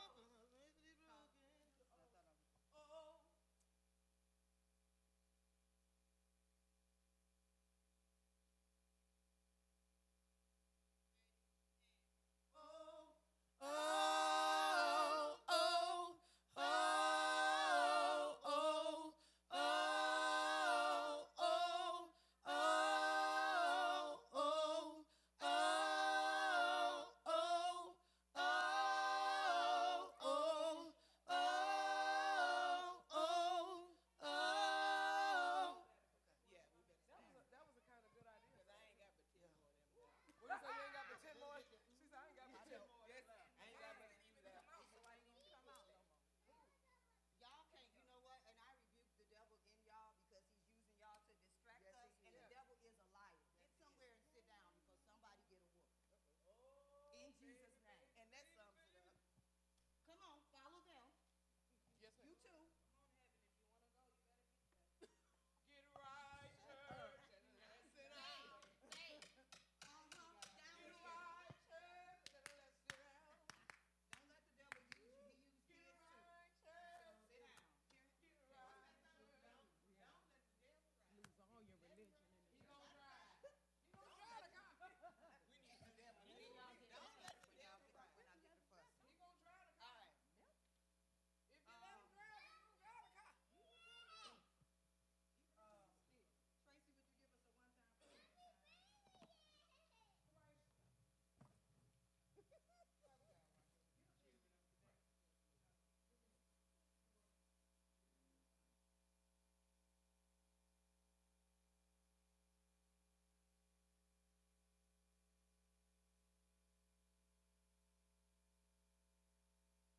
Sunday Morning Service 1/12/25